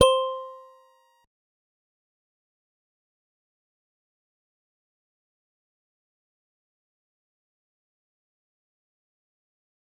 G_Musicbox-C5-mf.wav